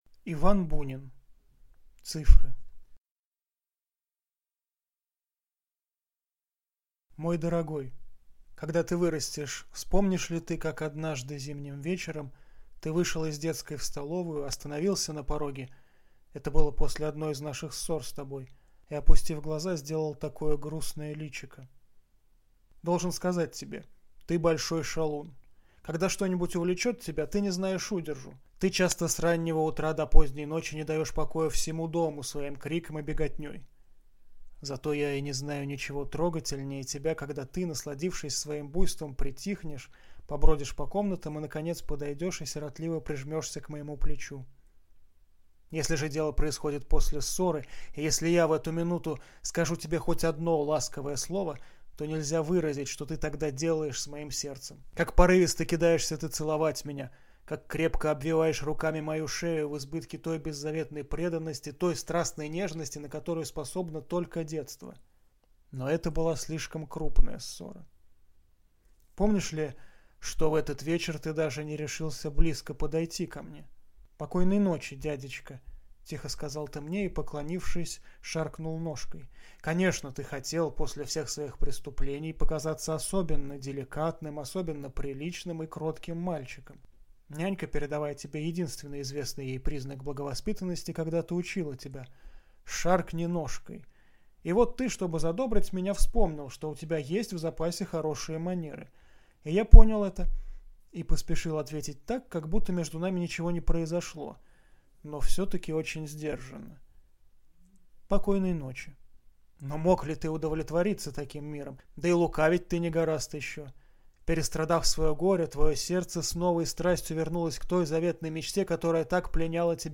Аудиокнига Цифры | Библиотека аудиокниг